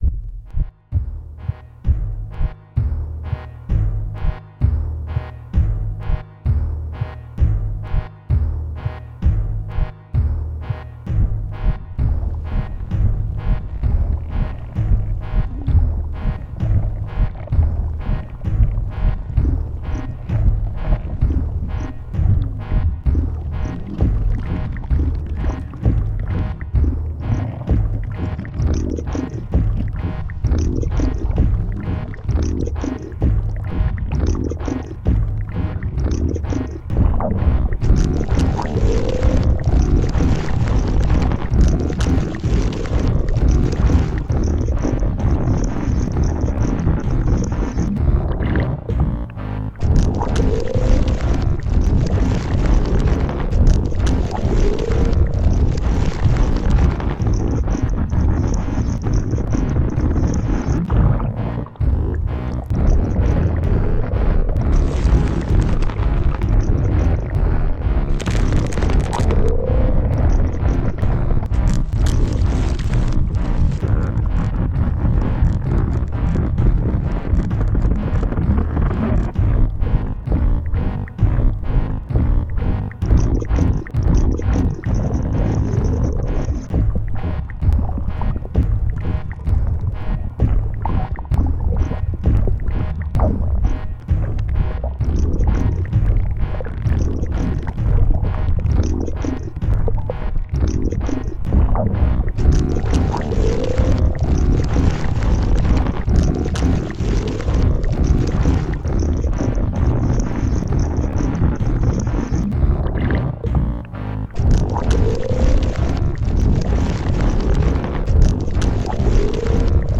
now with growth sounds for a great growth experience.